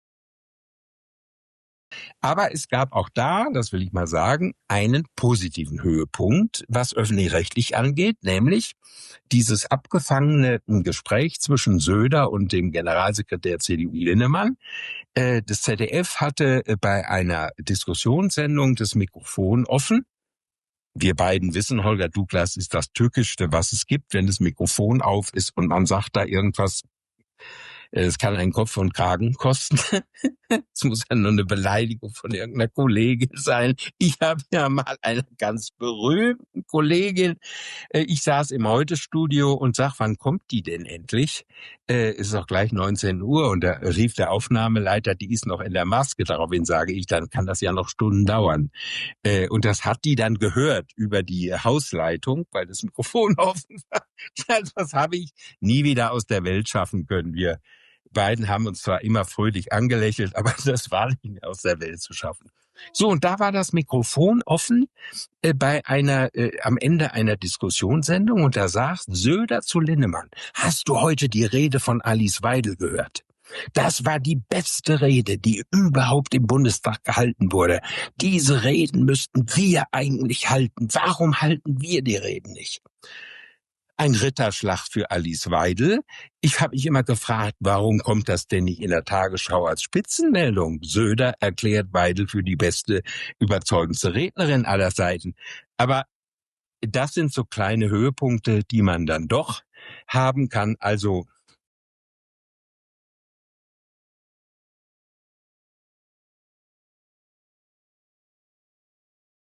Offenes Mikro und die reine Wahrheit